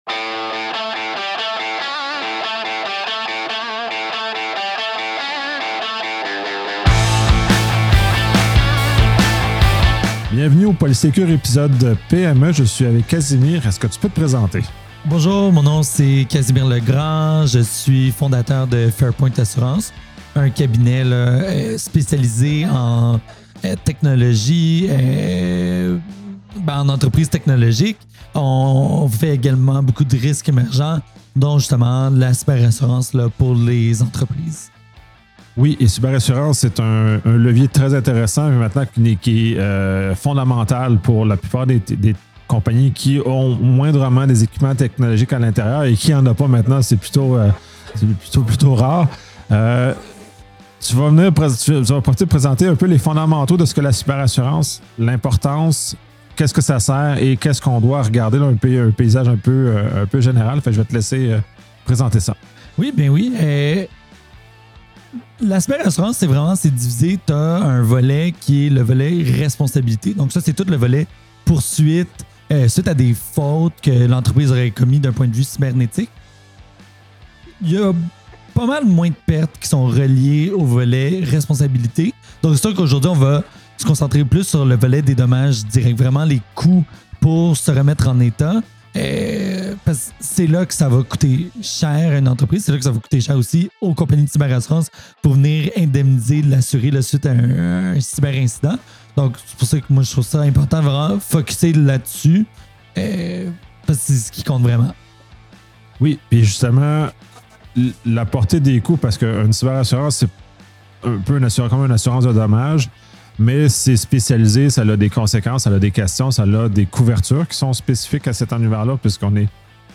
Locaux réels par Bagel Maguire Café